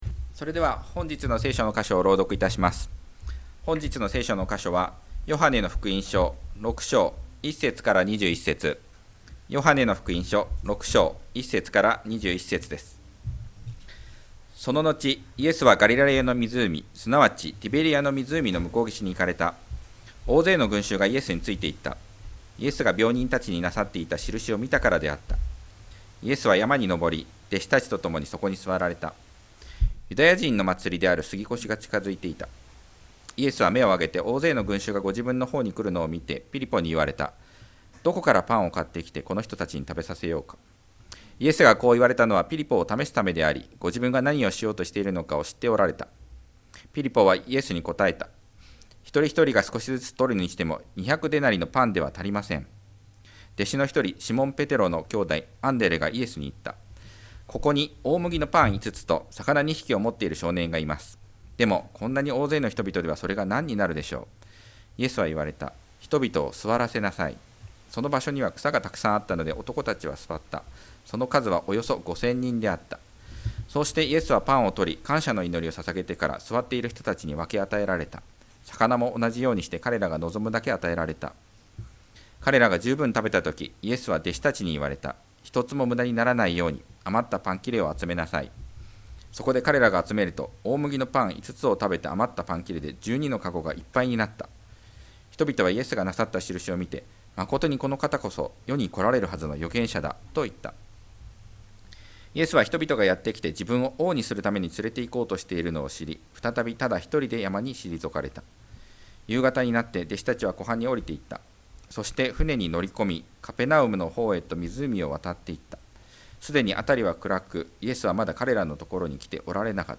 2025年7月20日礼拝 説教 「憐れみ深くもてなすイエス様」 – 海浜幕張めぐみ教会 – Kaihin Makuhari Grace Church
聖書の話 Sermon